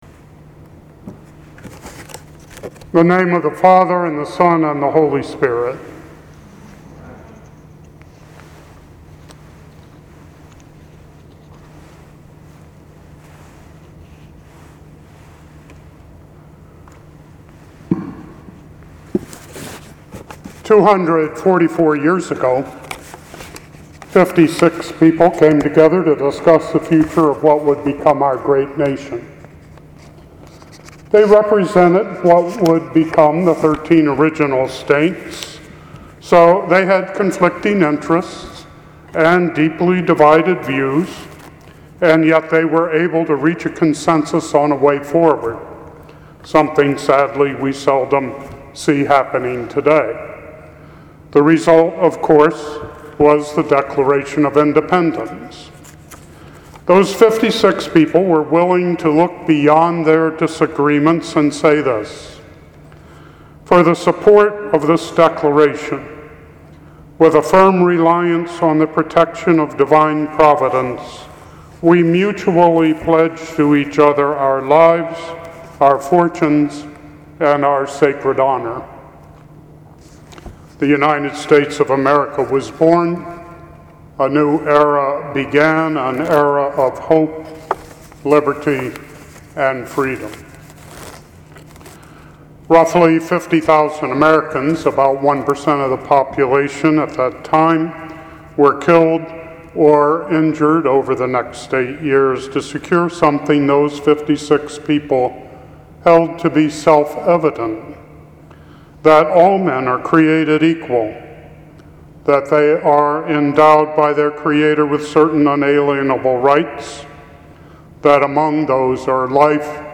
In today’s sermon